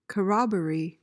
PRONUNCIATION:
(kuh-ROB-uh-ree)